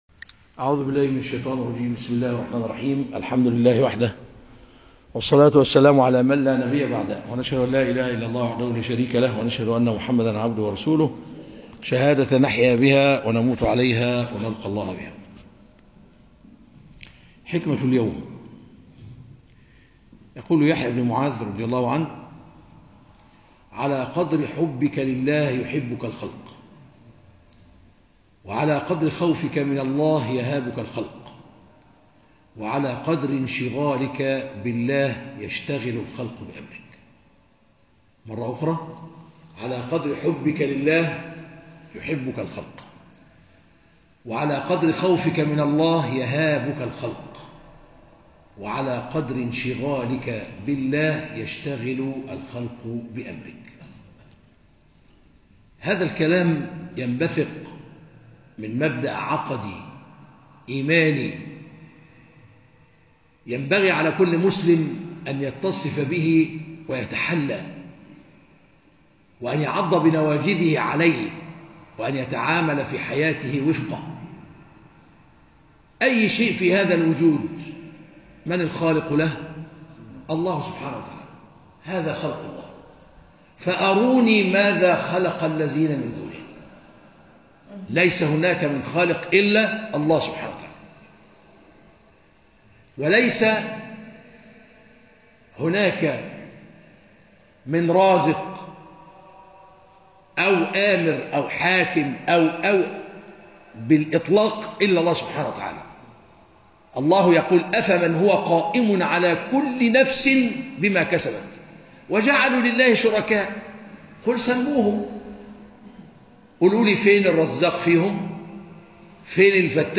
خاطرة حول (على قدر حبك لله يحبك الخلق) - الشيخ طلعت عفيفى